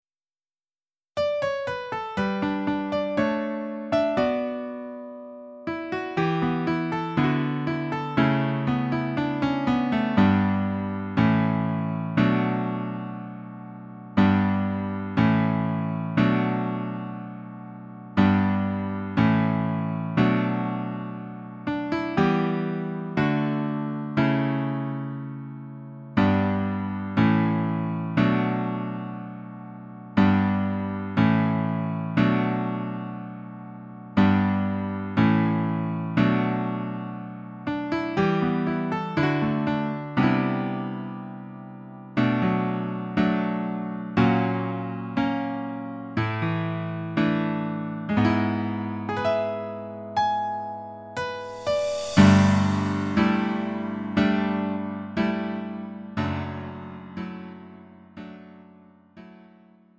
음정 원키 4:00
장르 구분 Lite MR